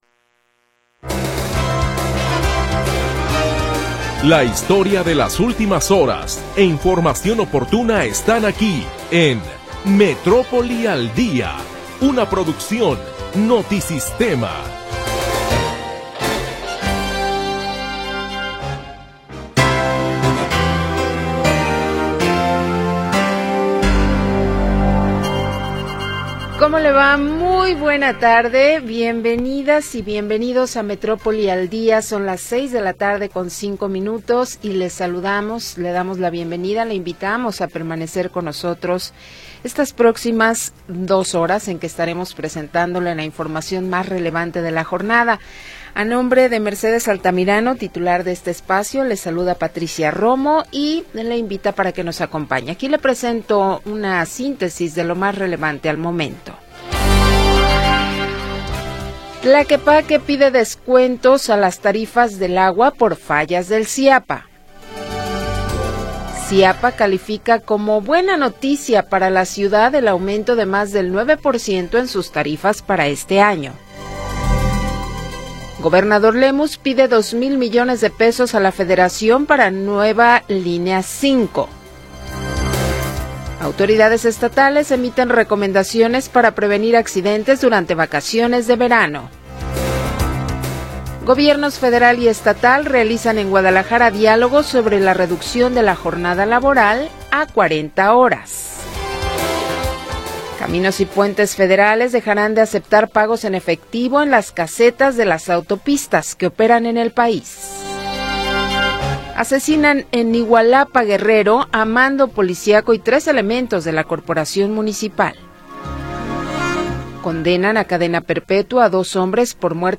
Primera hora del programa transmitido el 27 de Junio de 2025.